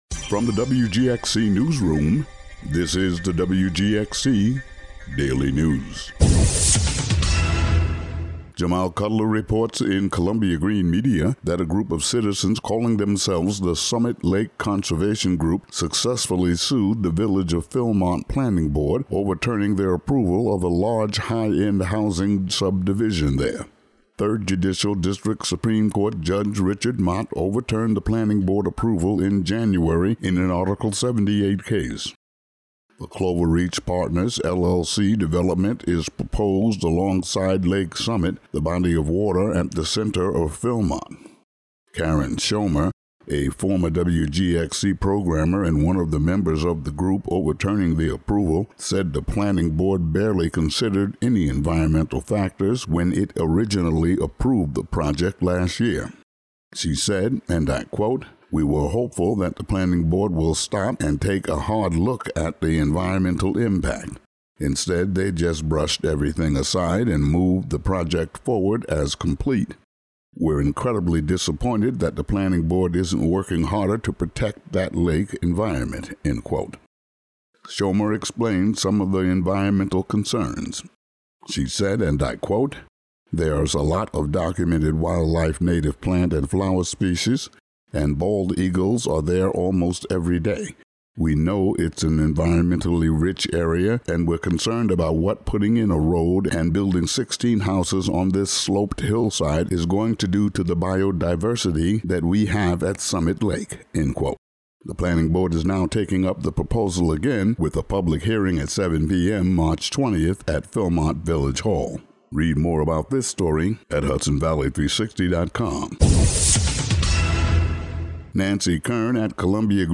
Today's daily local audio news.